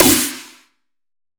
JPGC_slap_flat_earth_snare.wav